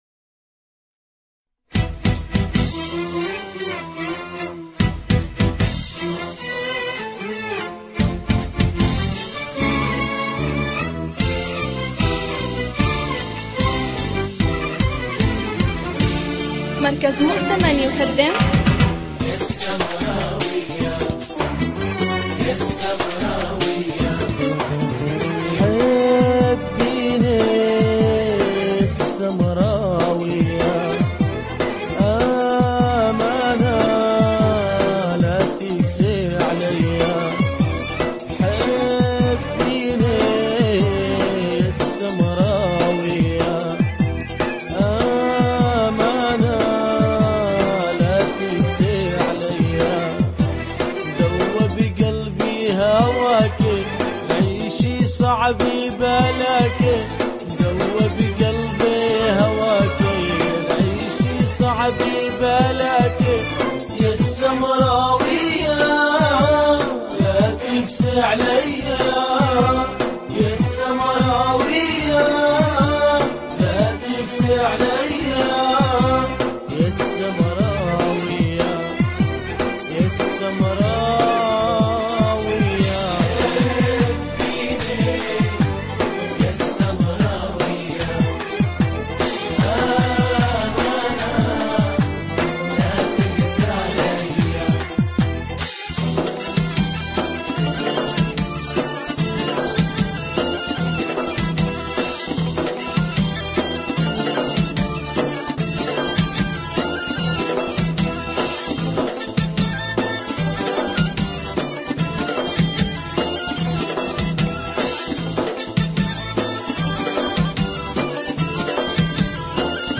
اغاني سوريه